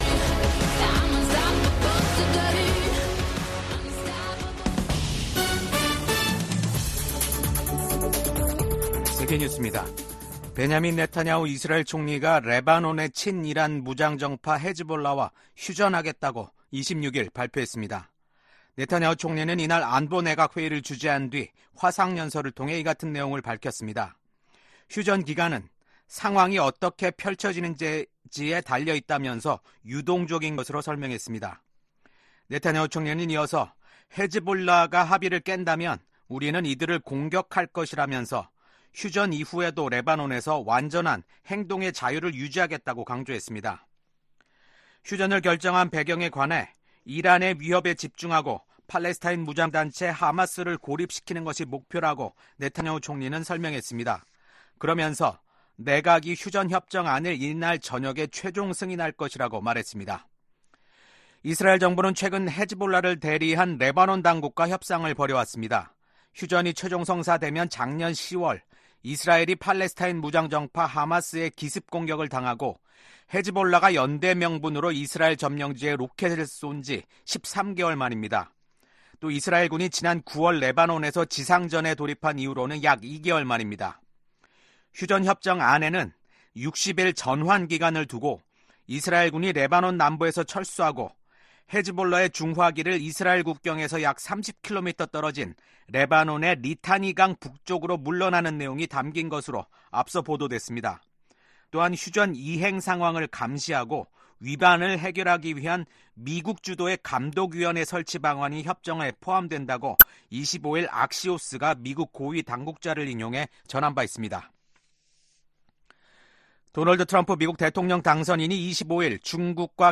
VOA 한국어 아침 뉴스 프로그램 '워싱턴 뉴스 광장'입니다. 미국은 북한군이 현재 우크라이나로 진격하지는 않았다고 밝혔습니다. 러시아가 북한에 파병 대가로 이중용도 기술과 장비를 판매하고 있다고 미 국무부가 밝혔습니다.